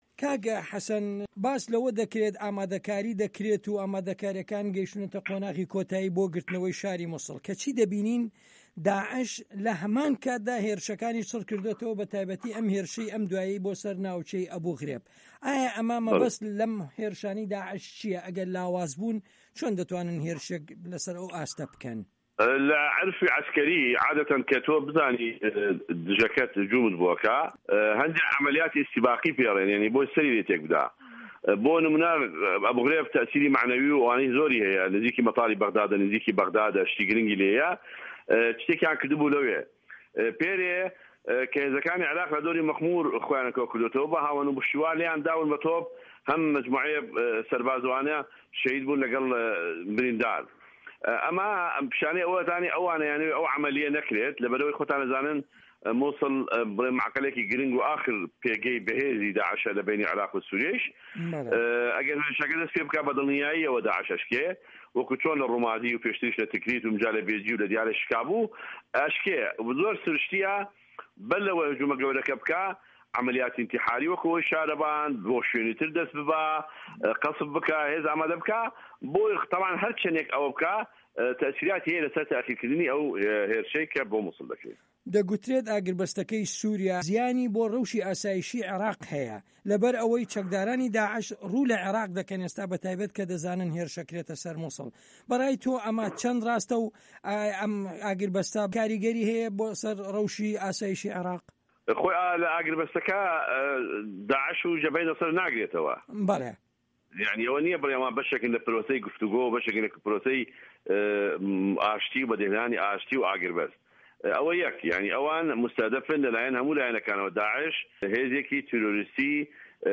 وتوێژ لەگەڵ حەسەن جیهاد